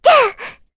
Luna-Vox_Attack1.wav